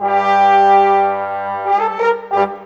Rock-Pop 07 Brass 02.wav